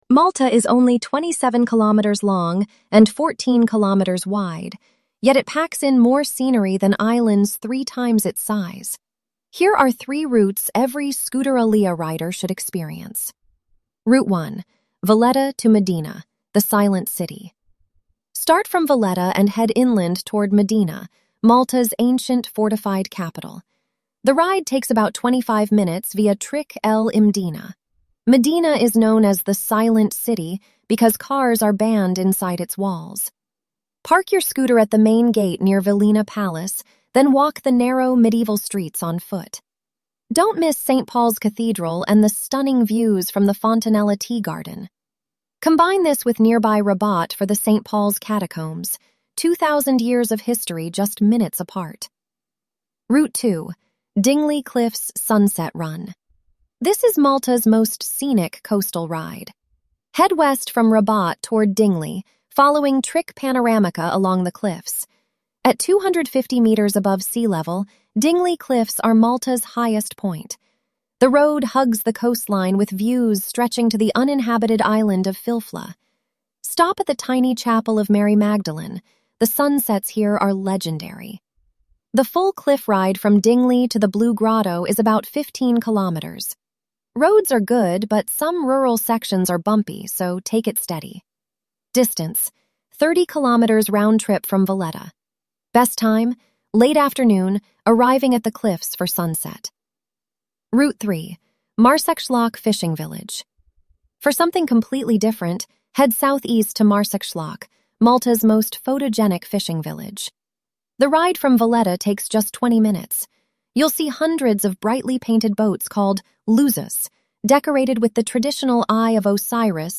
🎧 Malta Scooter Riding Audio Guide